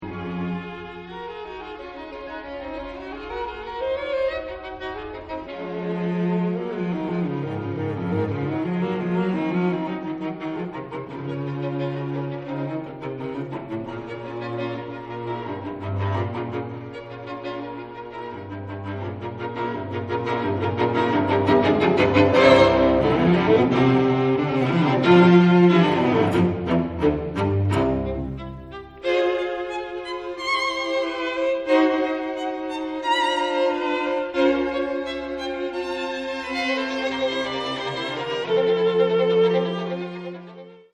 muziektheorieanalyse klassieke stukken  > Beethoven: strijkkwartet in F gr.t.  op. 59 nr.1